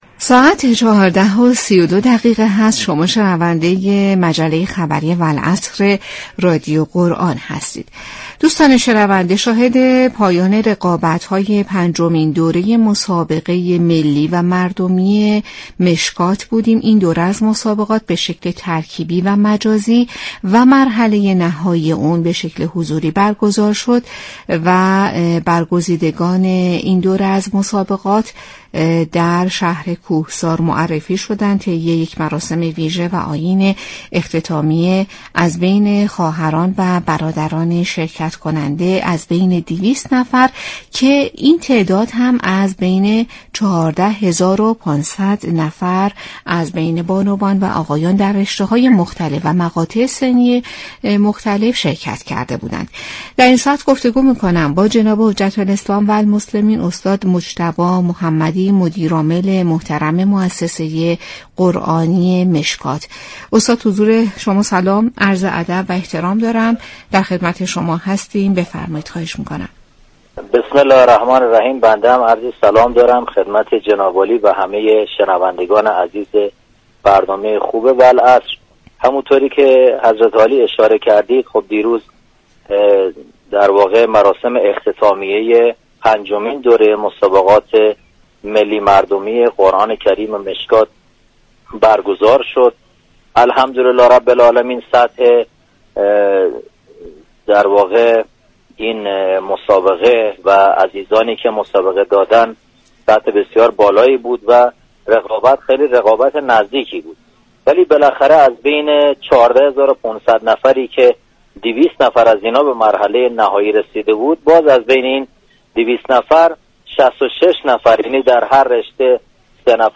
گفتنی است؛ جنگ عصرگاهی "والعصر" كه با رویكرد اطلاع رسانی یكشنبه تا چهارشنبه ی هر هفته بصورت زنده از رادیو قرآن پخش می شود.